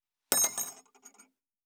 255,食器にスプーンを置く,ガラスがこすれあう擦れ合う音,カトラリーの音,食器の音,会食の音,食事の音,カチャン,コトン,効果音,環境音,BGM,
コップ効果音厨房/台所/レストラン/kitchen物を置く食器